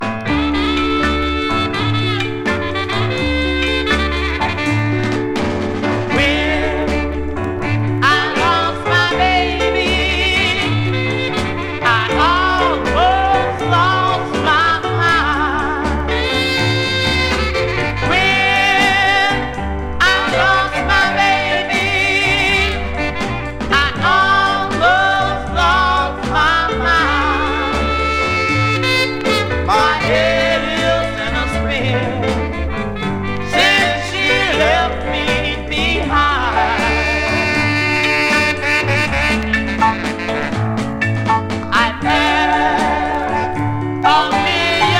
Zydeco　UK　12inchレコード　33rpm　Mono